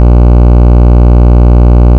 Sawtooth Waves
The more multiples we give, the harsher the sound, because the more harmonics are being added.